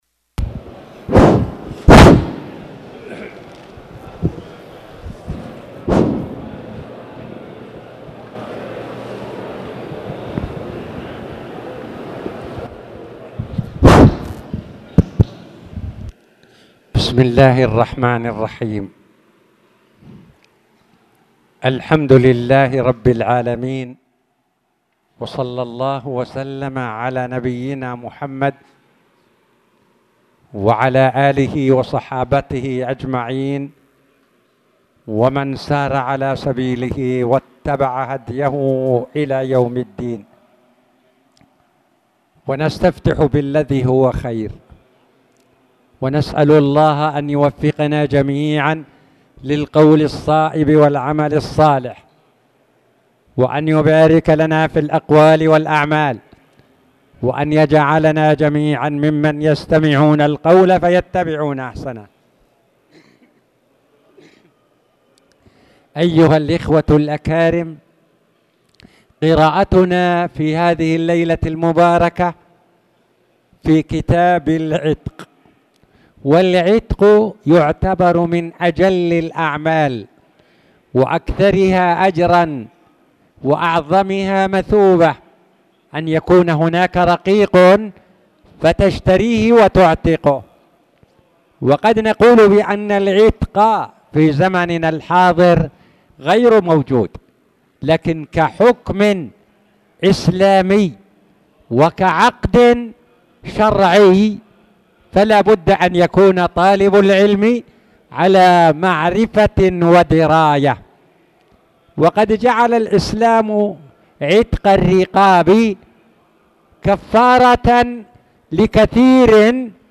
تاريخ النشر ٢٥ ذو الحجة ١٤٣٧ هـ المكان: المسجد الحرام الشيخ